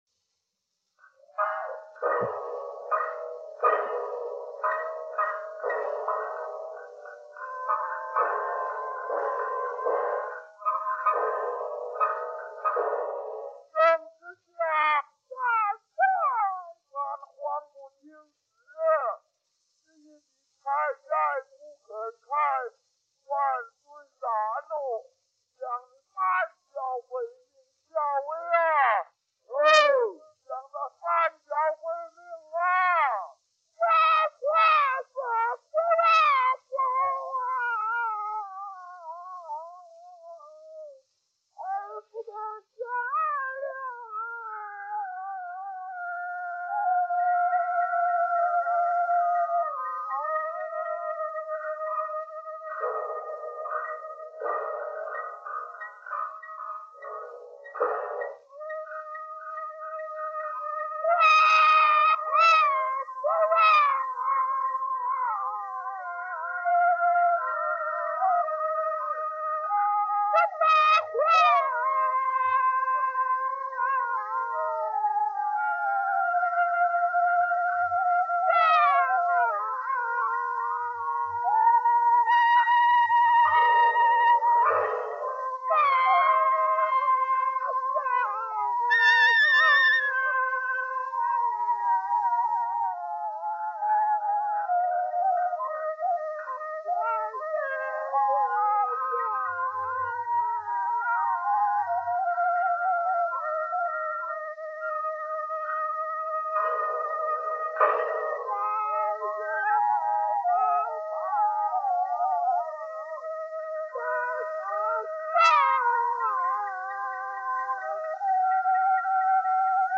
由印第安纳大学传统音乐档案馆蜡筒SCY2740与SCY2808修复合成
合成后“立体声”录音：81条
《大香山-七段（立体声）》.mp3